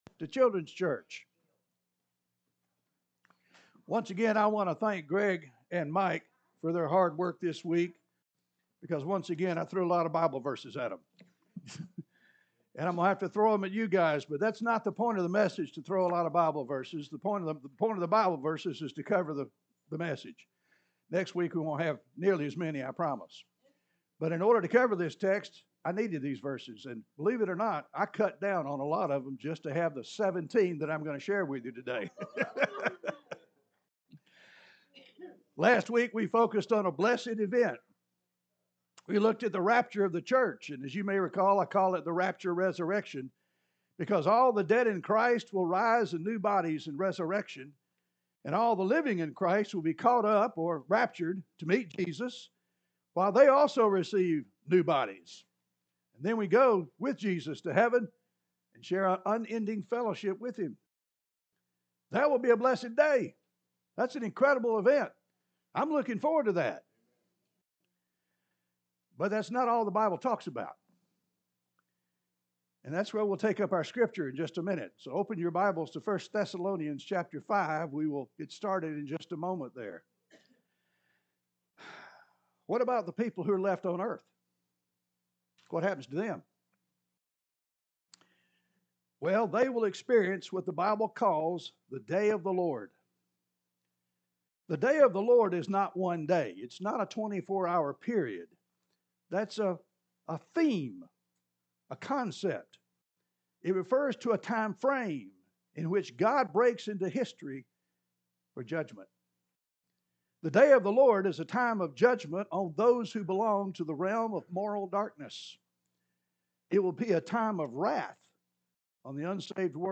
TRUTH IN THESSALONIANS (Sermon Series)